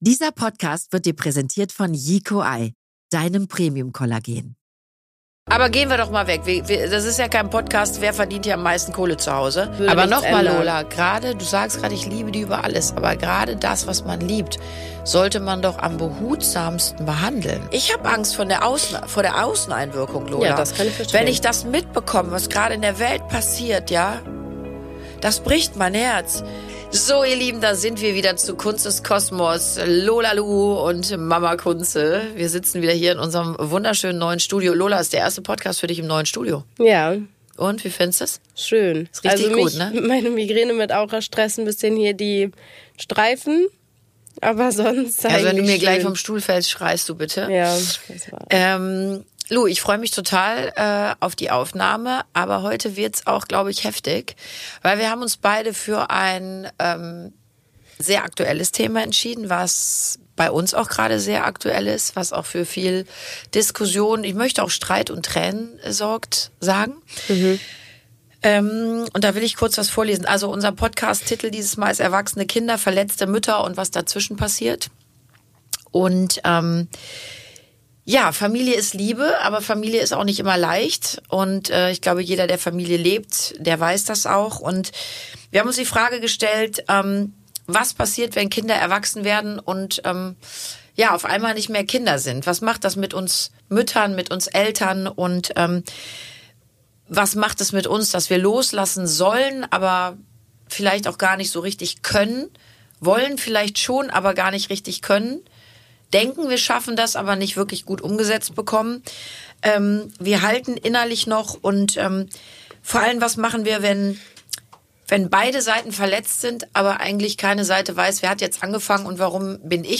Ein ehrliches Gespräch zwischen Mutter und Tochter über Erwartungen, verletzte Gefühle und die Entscheidung, immer wieder aufeinander zuzugehen.